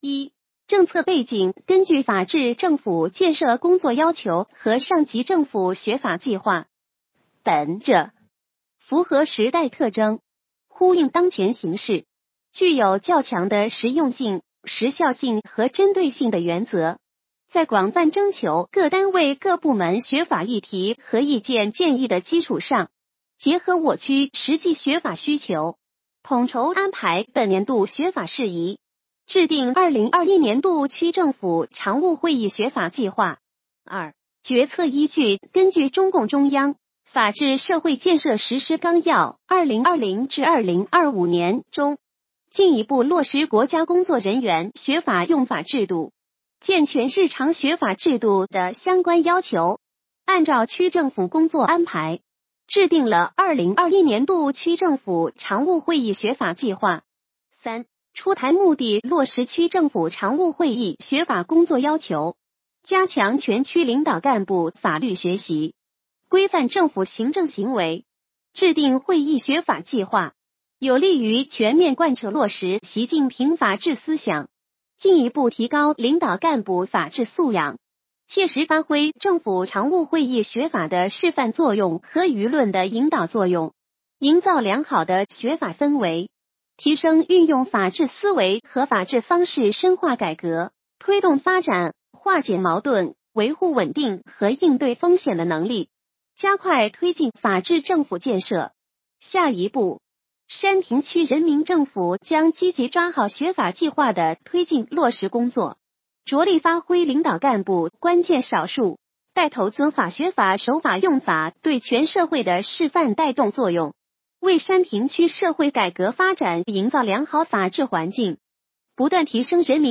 语音解读：山亭区人民政府办公室关于印发2021年度区政府常务会议学法计划的通知